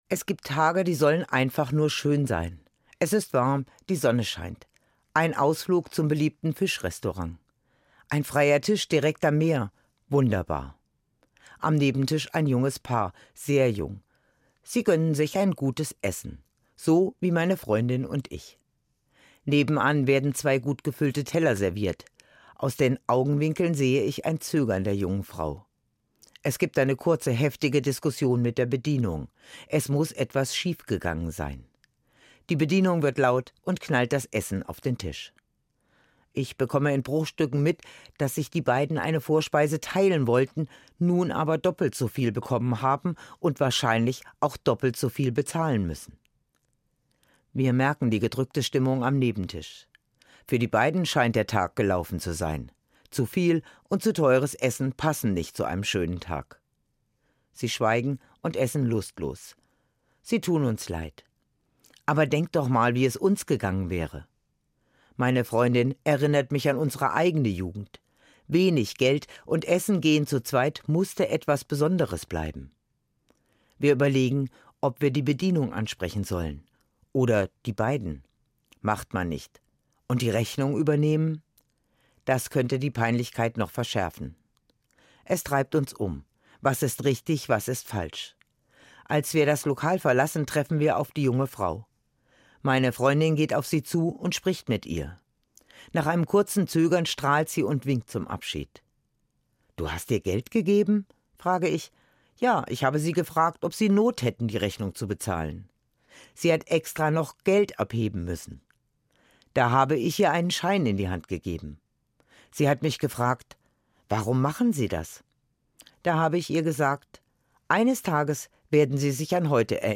Geprochen von Pfarrerin